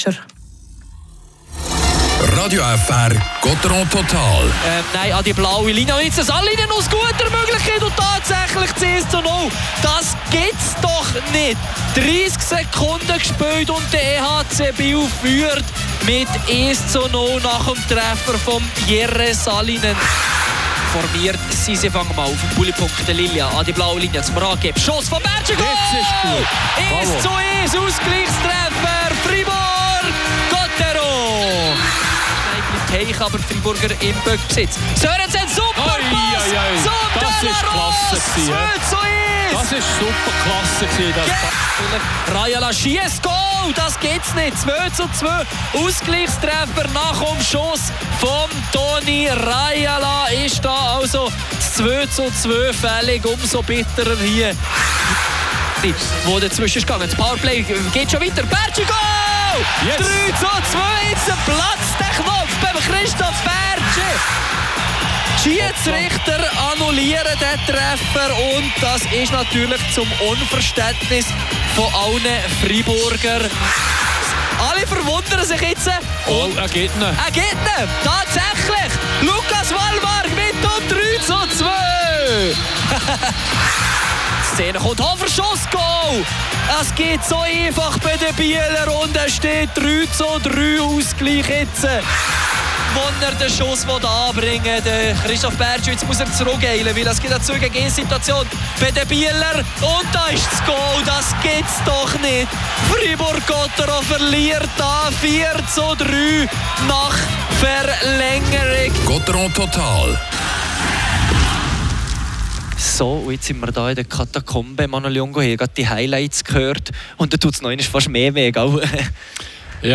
Ob dieser eine Punkt reicht, um den EHC Kloten in Schach zu halten, bleibt offen. Spielanalyse
Interview